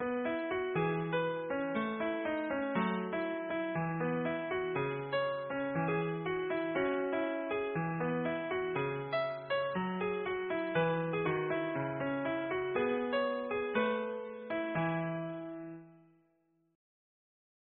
- Music Box Songs